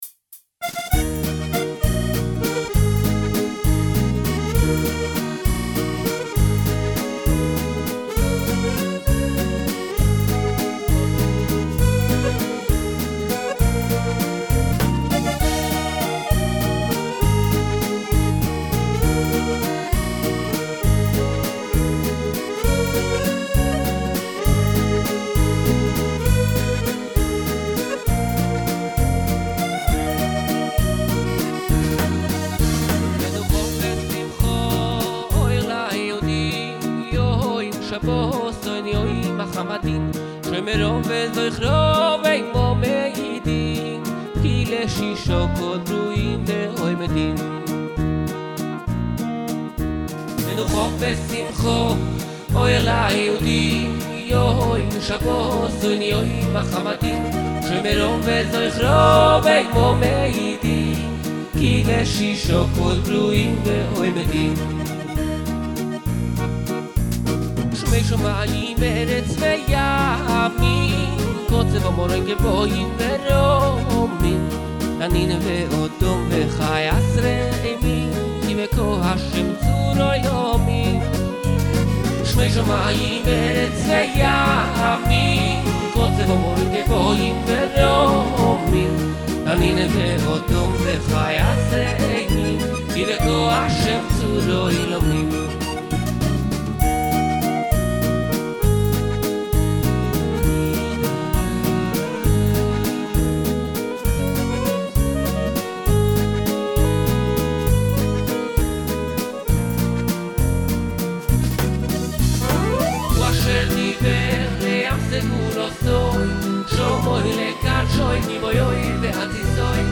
לחן וולס חדש מקסים וקליט